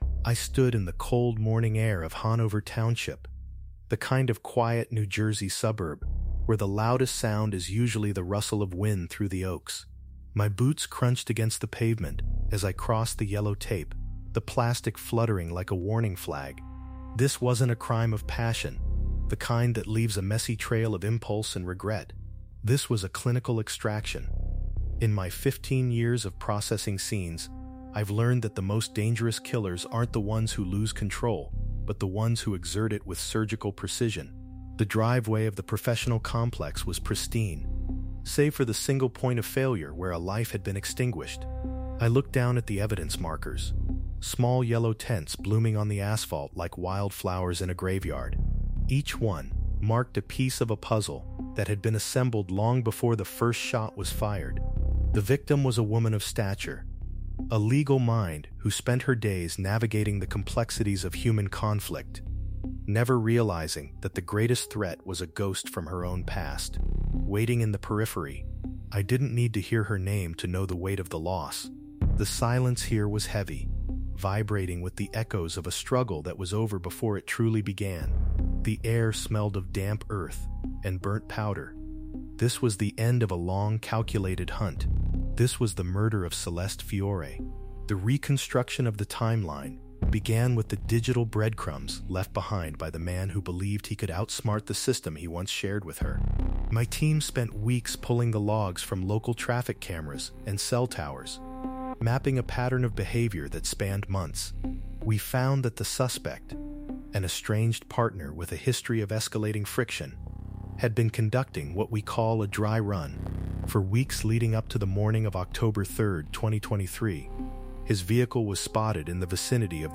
Through first-person detective narration, we examine the forensic evidence recovered from encrypted drives and digital logs that dismantled the defense’s claims of a sudden psychological break.